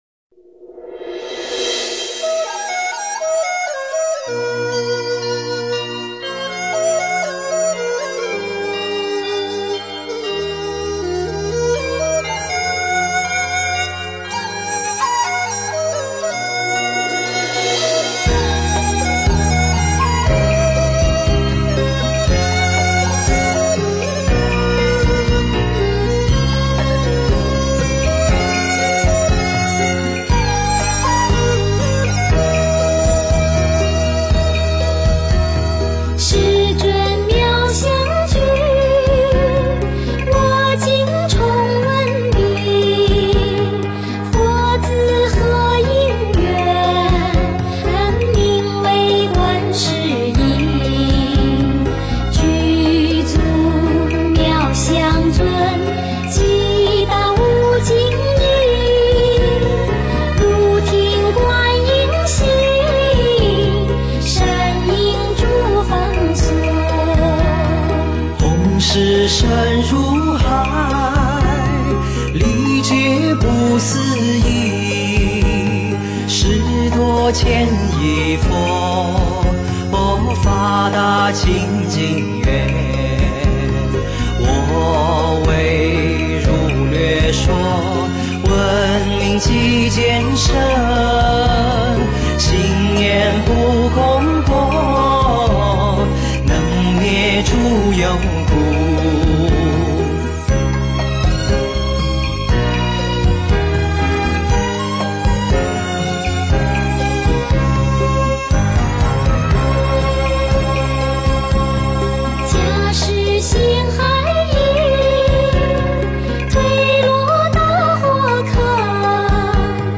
观音普门品 - 诵经 - 云佛论坛
观音普门品 诵经 观音普门品--佛教音乐 点我： 标签: 佛音 诵经 佛教音乐 返回列表 上一篇： 地藏经 下一篇： 观音行 相关文章 遥呼上师颂--王菲 遥呼上师颂--王菲...